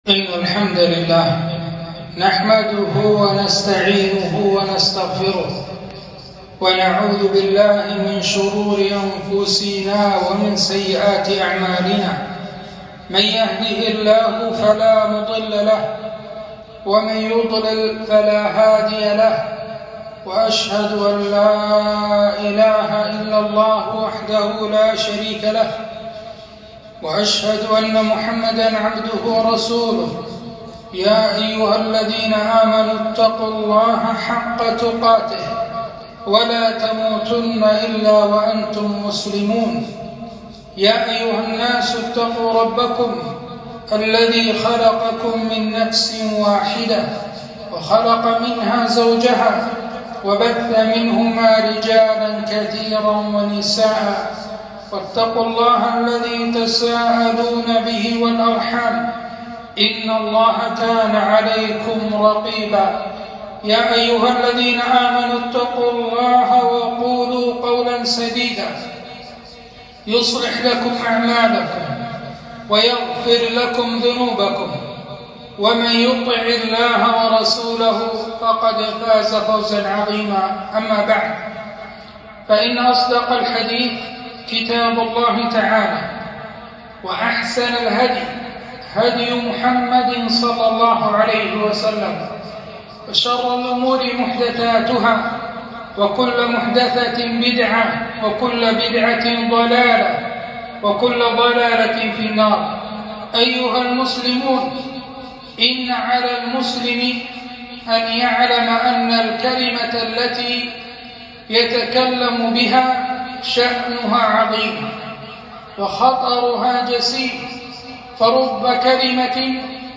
خطبة - خطر الشائعات